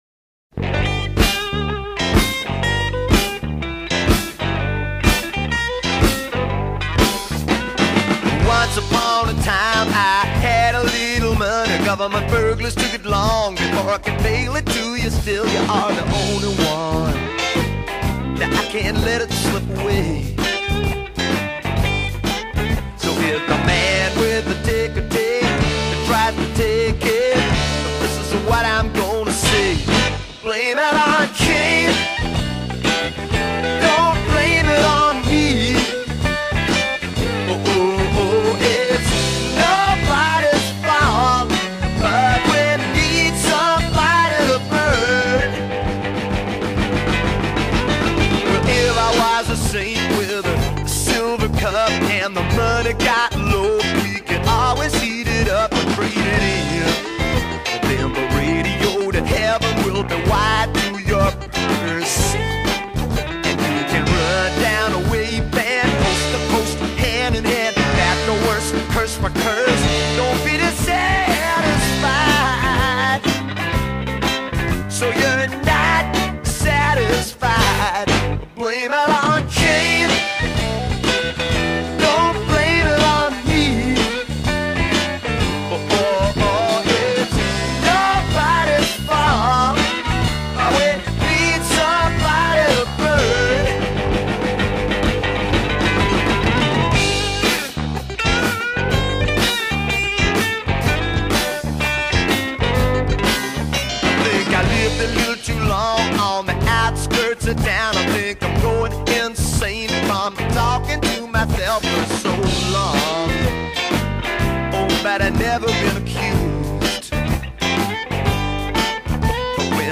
are crammed with hooky numbers.
with its leisurely swing and Steely Dan guitar lines